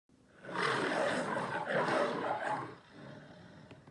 Roar Of A Lion Bouton sonore